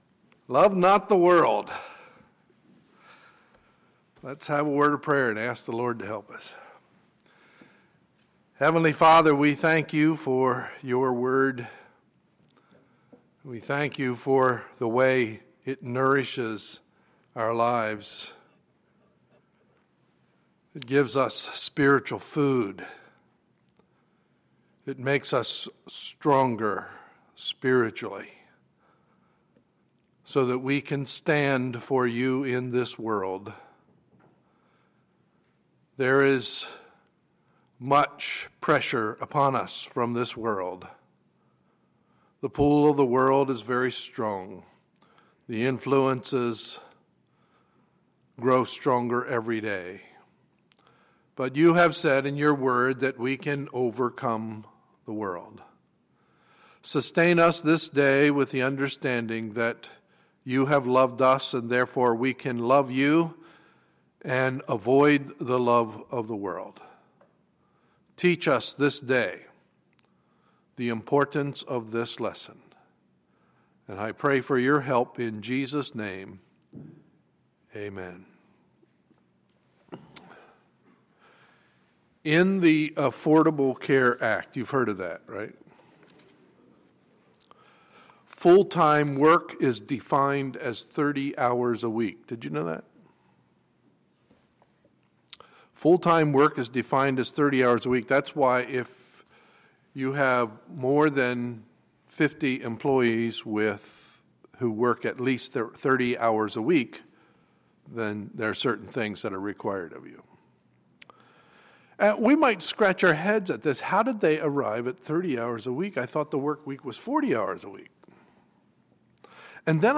Sunday, August 17, 2014 – Morning Service